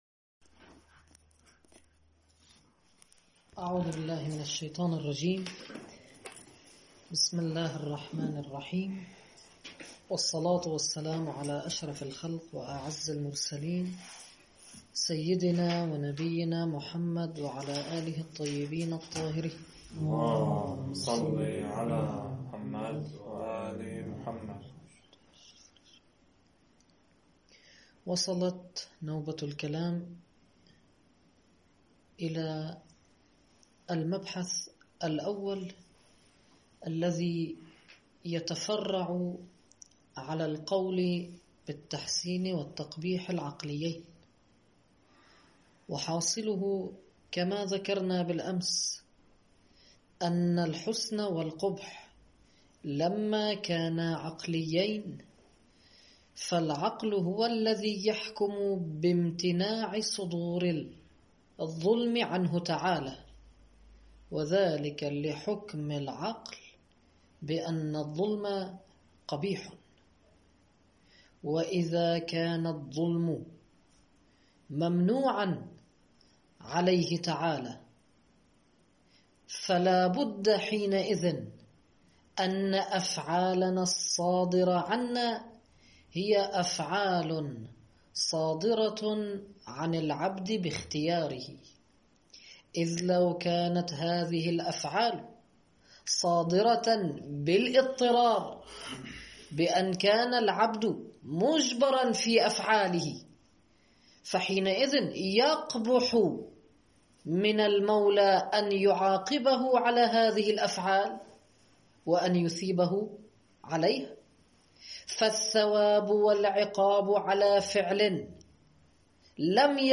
صوت الدرس